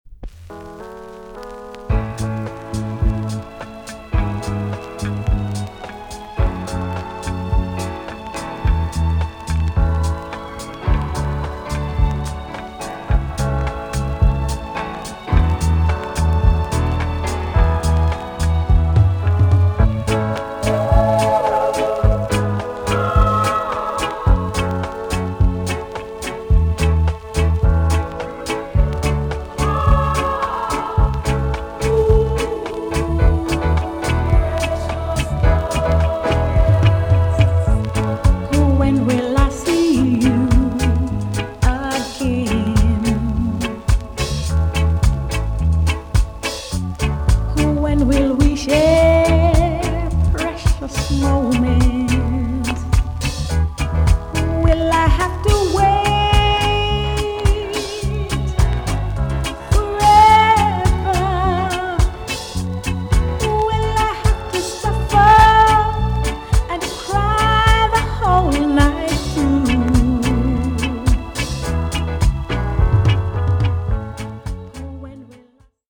TOP >REGGAE & ROOTS
EX- 音はキレイです。
SWEET VOCAL TUNE!!